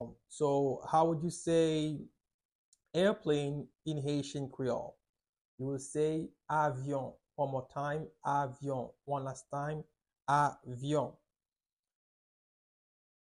Pronunciation:
1.How-to-say-Airplane-in-Haitian-Creole-–-Avyon-pronunciation.mp3